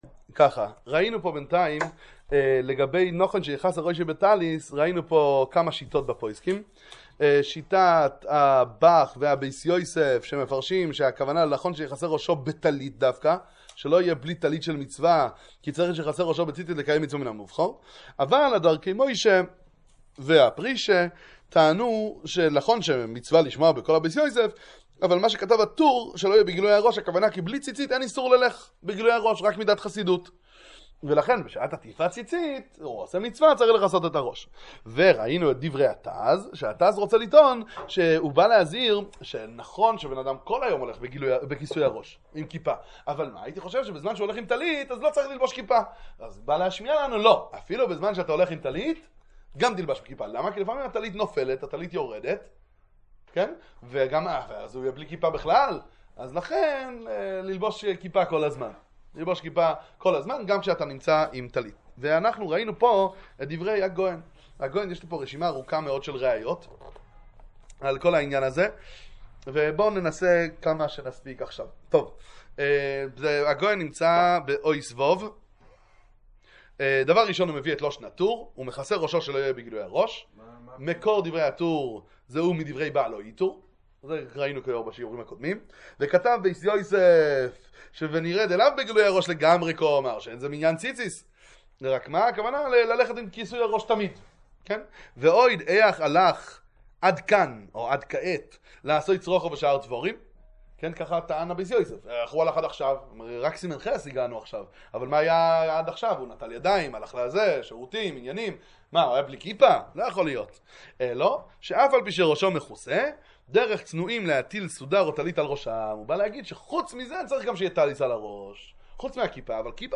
שיעור בשולחן ערוך עם ביאור הגר"א הלכות ציצית